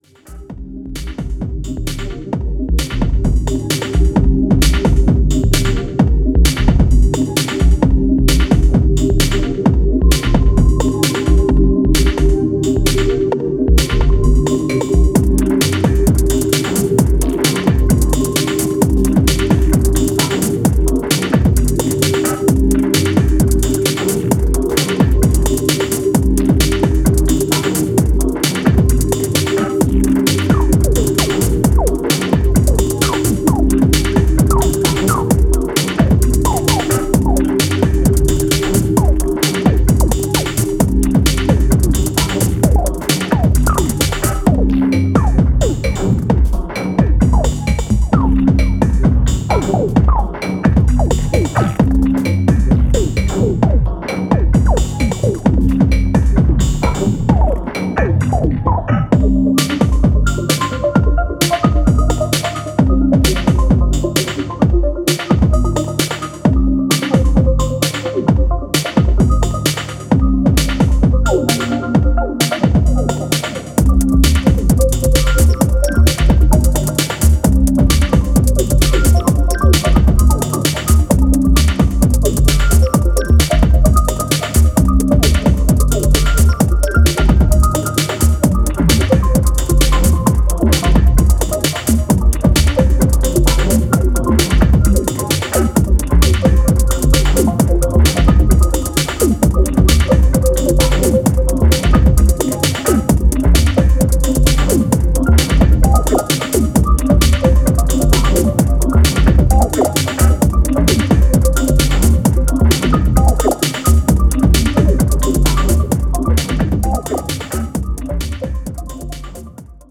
Genre Electro , House